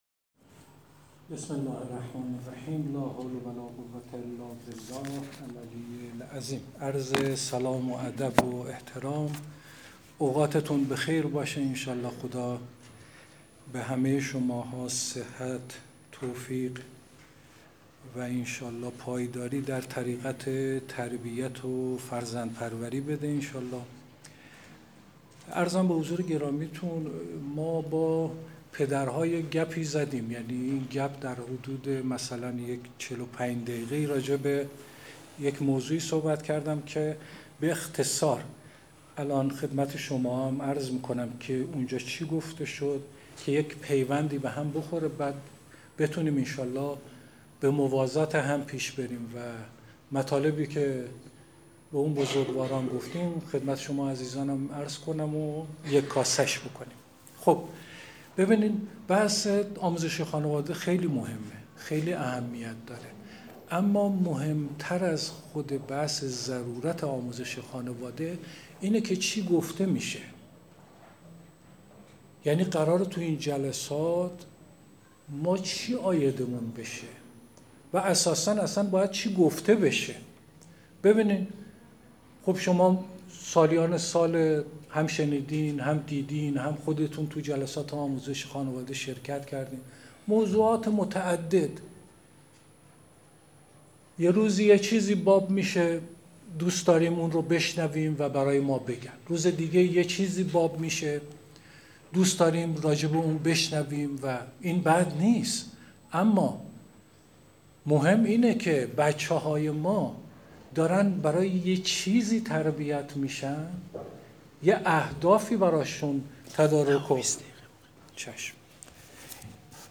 سخنرانی
جلسه عمومی مادران دبیرستان یک و دو - 1 آذر ماه 1402 - روی لینک آبی رنگ کلیک کنید دانلود شود: فایل صوتی - فایل پی دی اف